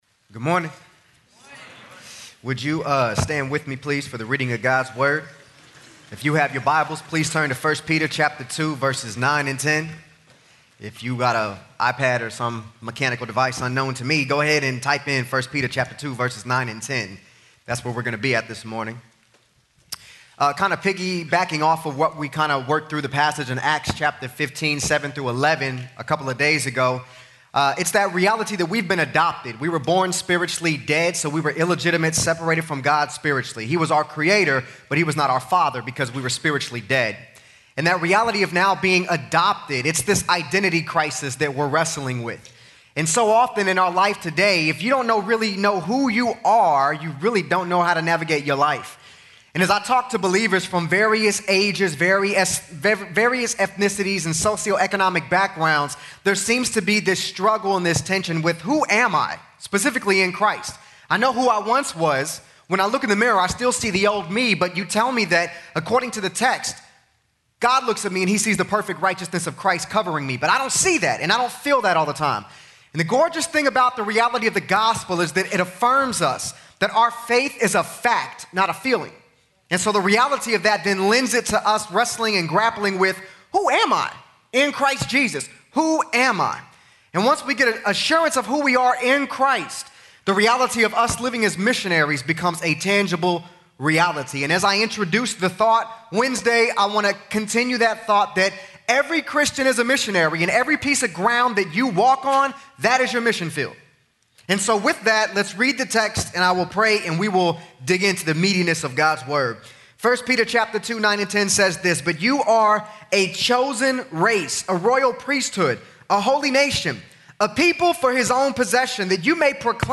GO Week Chapel